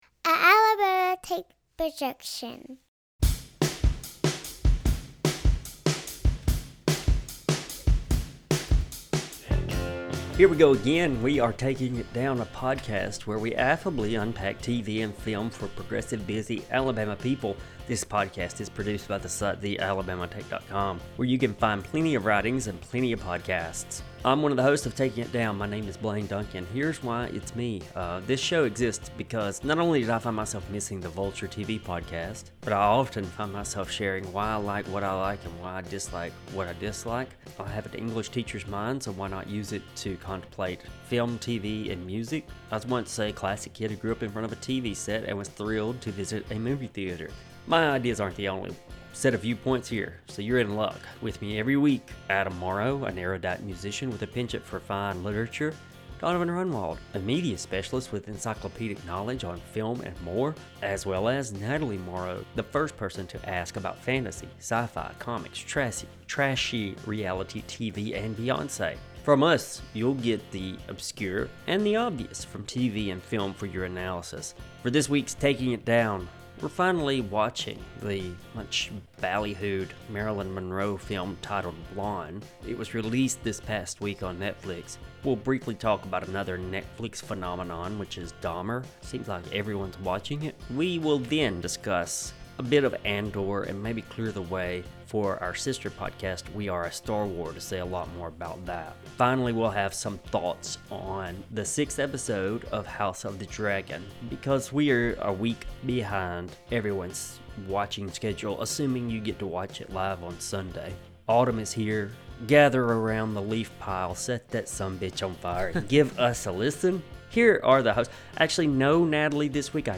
Welcome back, Taking It Downers! This week, it's some brief banter about sports, namely college football ( 2:47 ) before some heady and serious discussions begin centered around the film Blonde , which was recently released on Netflix ( 6:27 ). Keeping with the Netflix motif, the hosts briefly discuss what watching Dahmer says about us as a culture ( 21:23 ).